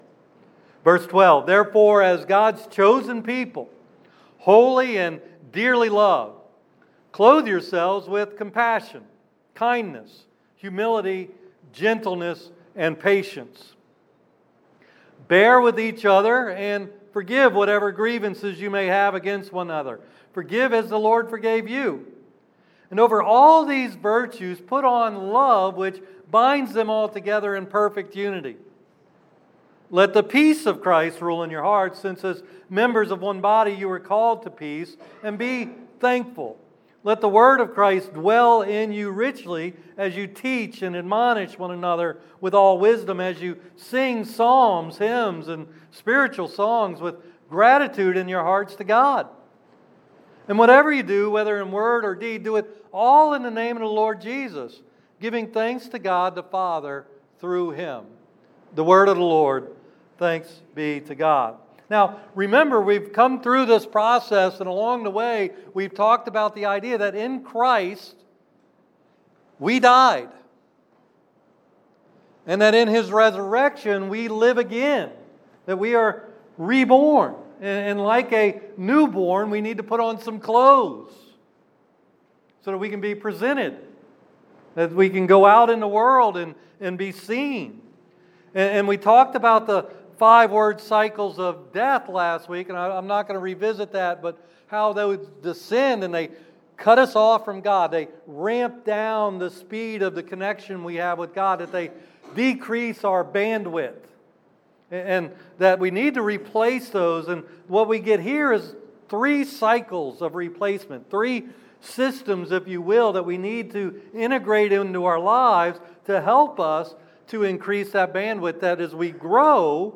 Colossians Sermons – The Bayou Christian